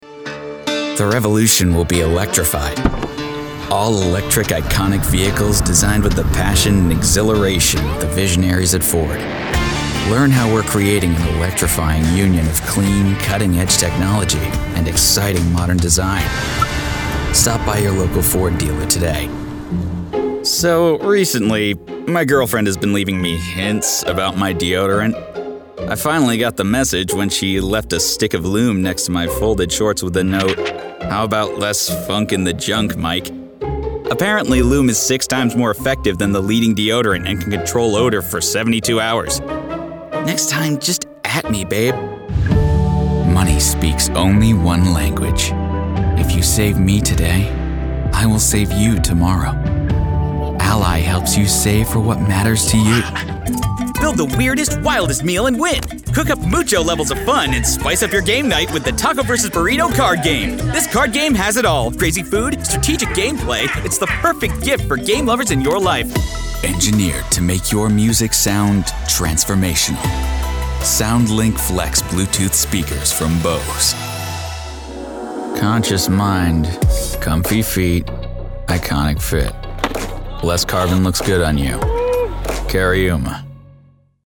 Adult, Young Adult
Fun, sporty, expressive and ready to help!
commercial